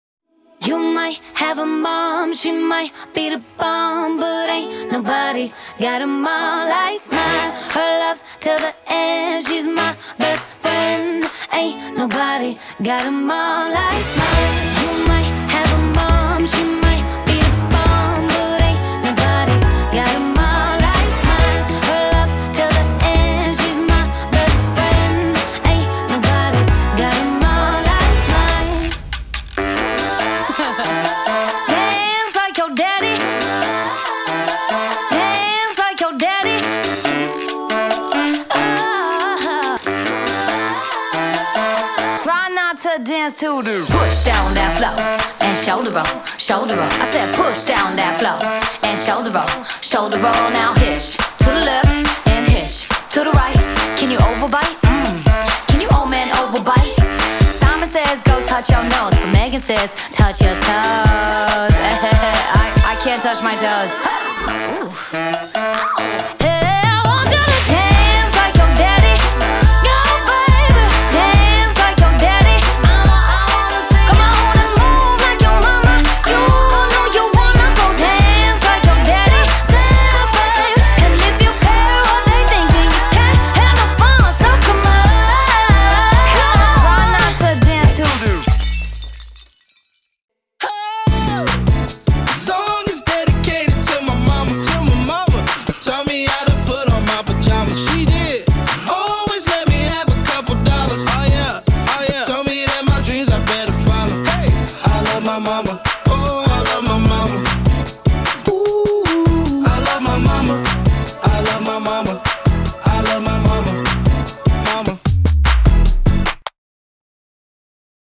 ISD-PRE-TEEN-LATIN-MOM-AND-DAD-V2.m4a